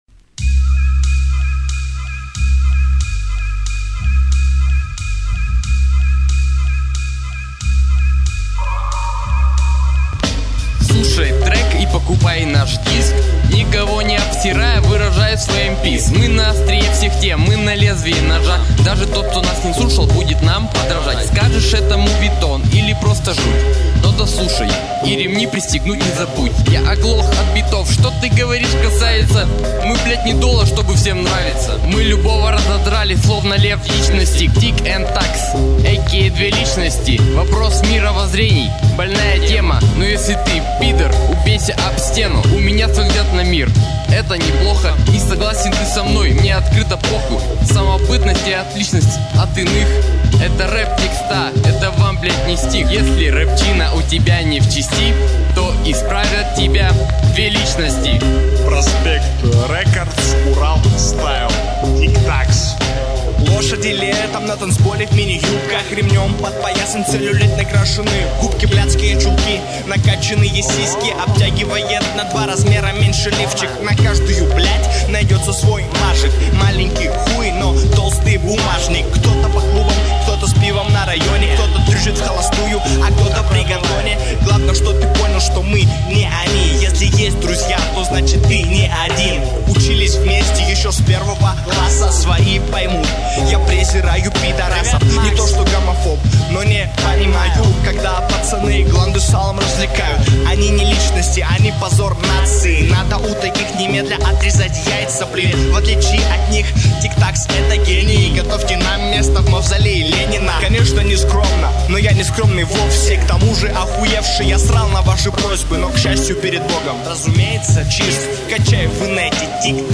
Музыкальный хостинг: /Рэп